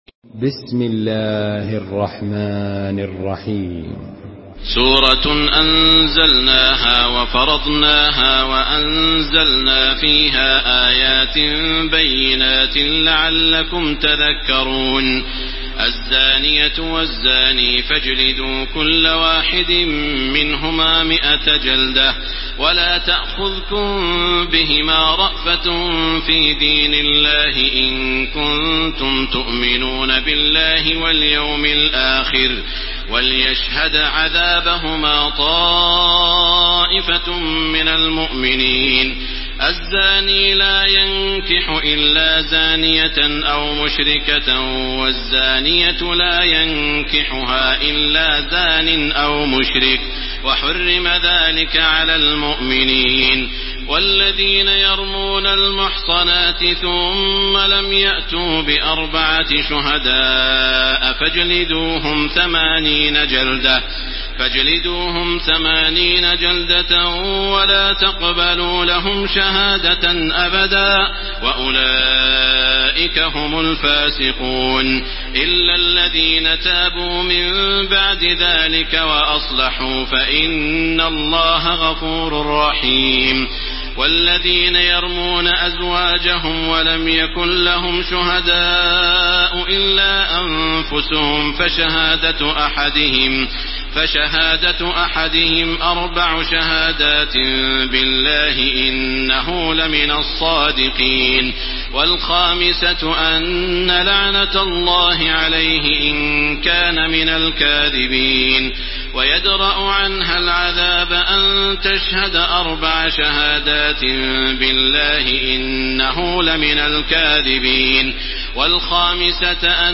تحميل سورة النور بصوت تراويح الحرم المكي 1431
مرتل حفص عن عاصم